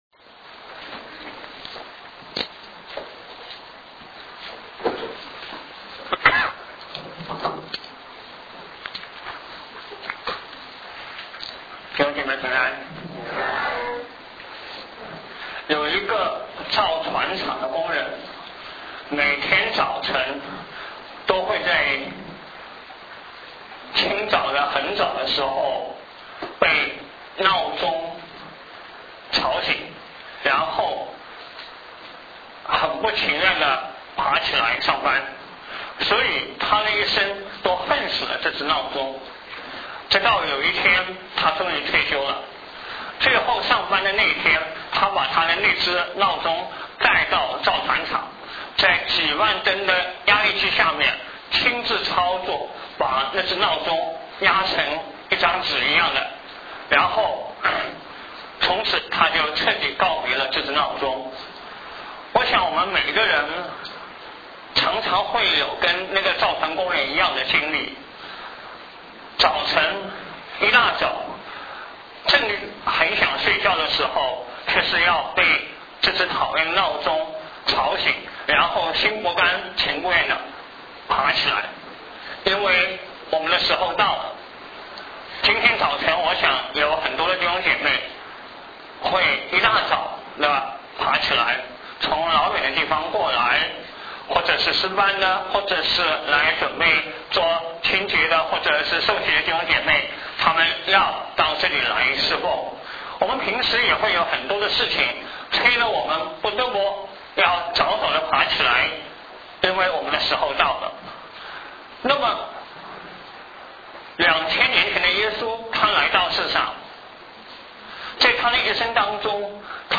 讲道：侍奉的建造——我们的时候到了（2007年6月3日，附音频）